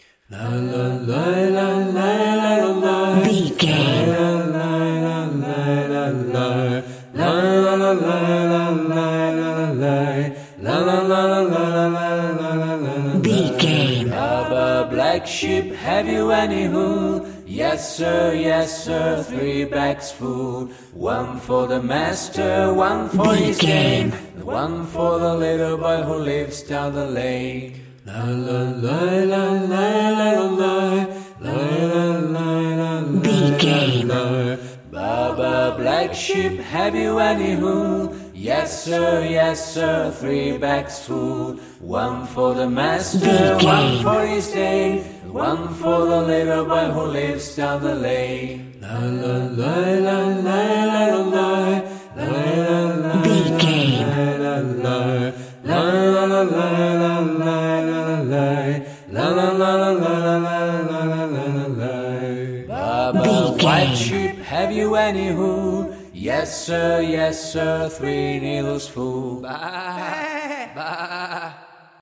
Ionian/Major
nursery rhymes
childlike
cute
happy